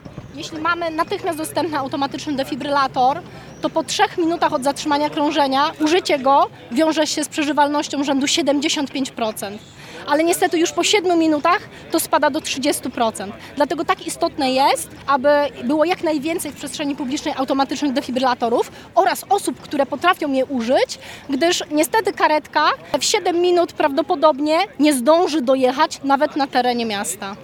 Jak zachować się w sytuacji zagrożenia zdrowia i życia, jak prawidłowo udzielać pierwszej pomocy, jak obsłużyć defibrylator. W centrum Szczecina odbyło się spotkanie z ratownikami i pokaz udzielania pierwszej pomocy przy użyciu AED.